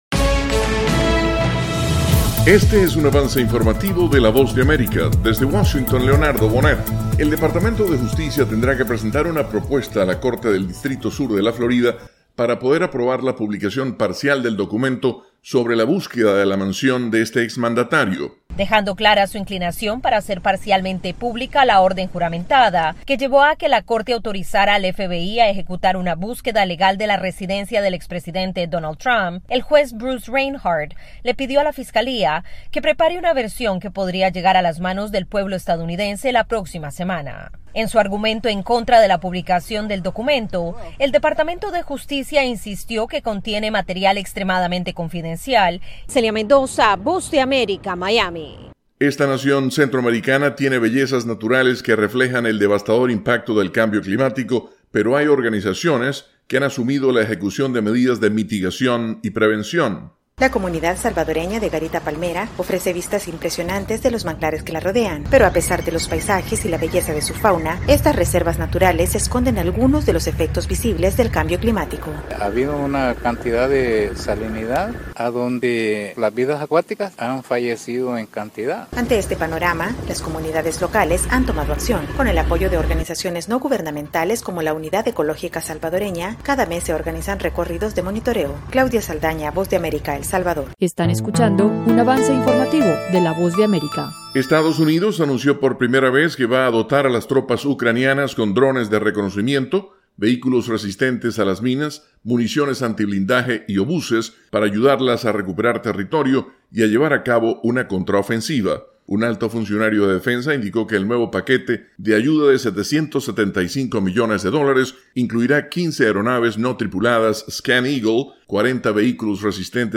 Avance Informativo - 7:00 PM
El siguiente es un avance informativo presentado por la Voz de América, desde Washington,